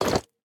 Equip_copper1.ogg